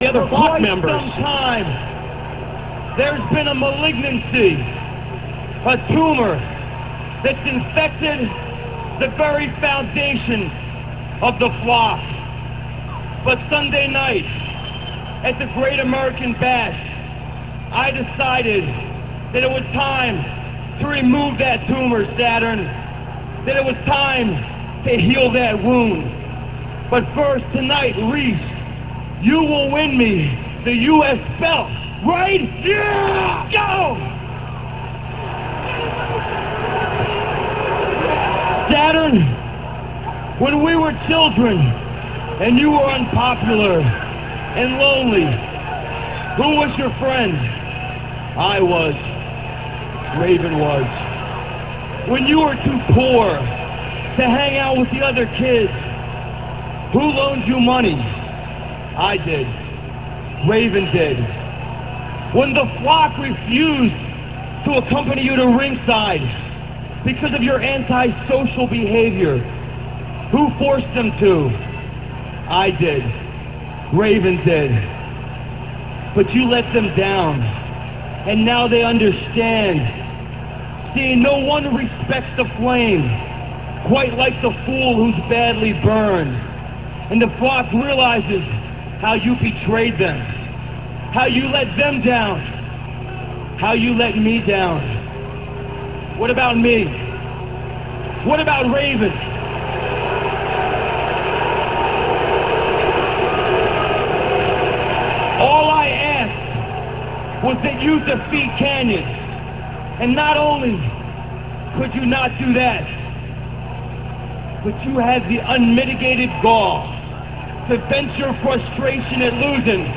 - From WCW Nitro - [06.15.98]. Raven fires Saturn from the Flock and says Saturn's attack on him after losing to Kanyon on The Great American Bash broke Raven's heart and for that he can never forgive him.